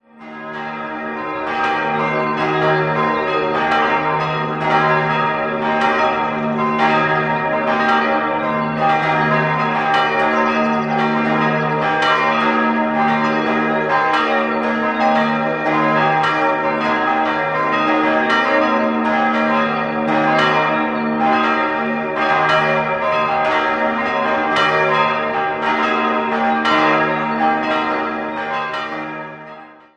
Die Kirche ist regulär nur sonntags geöffnet. 5-stimmiges erweitertes Salve-Regina-Geläute: es'-g'-b'-c''-es'' Die Glocken es' und c'' wurden 1965 von Georg Hofweber in Regensburg gegossen, über die drei übrigen liegen keine Informationen vor.